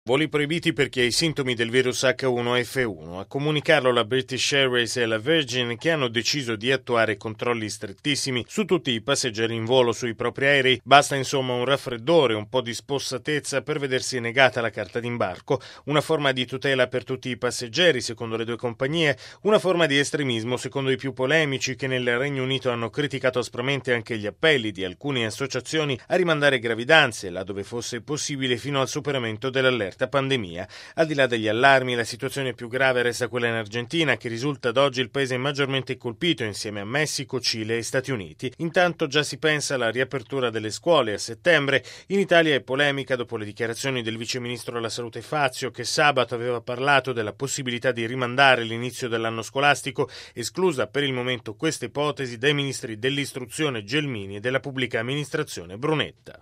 E mentre in tutto il mondo si moltiplicano i contagi, dalla Gran Bretagna arrivano misure severissime di controllo. Il servizio